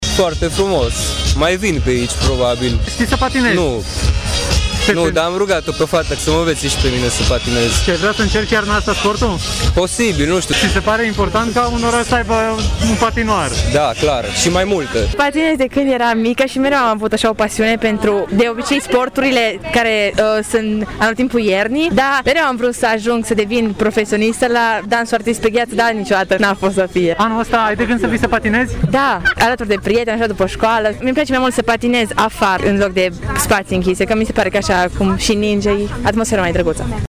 Tinerii târgumureșeni se arată mulțumiți de patinoar, spun că ar vrea să încerce sportul și abia așteaptă să intre pe gheață alături de prieteni: